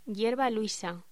Locución: Hierbaluisa